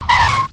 airutils_touch.ogg